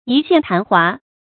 一現曇華 注音： ㄧ ㄒㄧㄢˋ ㄊㄢˊ ㄏㄨㄚ 讀音讀法： 意思解釋： 比喻事物或人物一出現就很快消失。